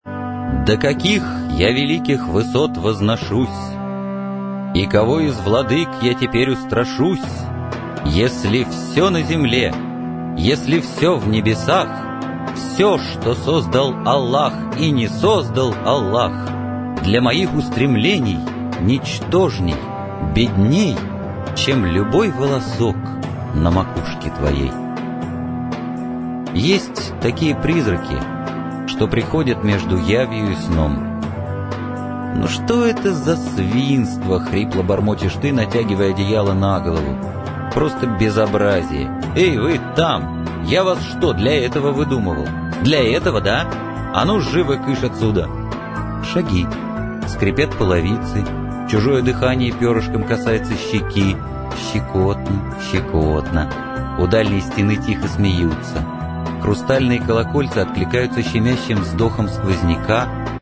Аудиокнига Я возьму сам | Библиотека аудиокниг